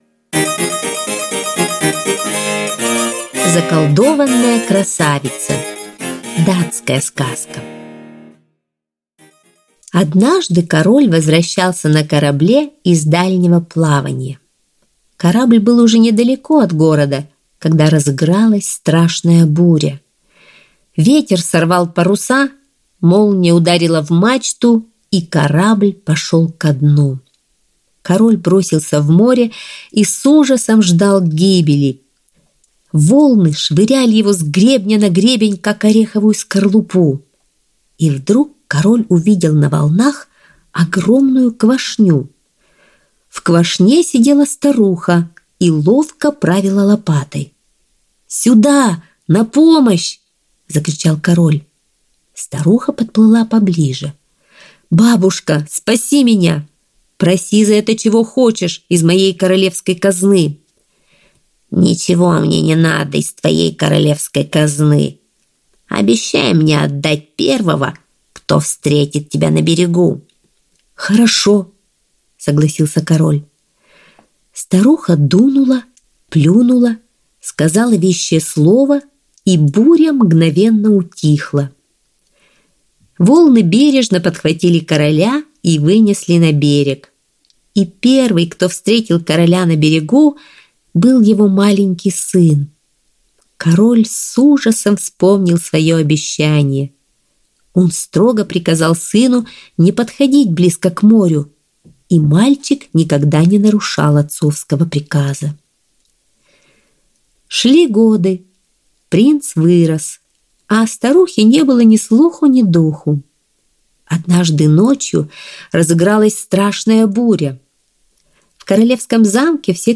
Заколдованная красавица - датская аудиосказка. Сказка о злой колдунье, спасшей короля, отправив его в домой в обмен на его маленького сына.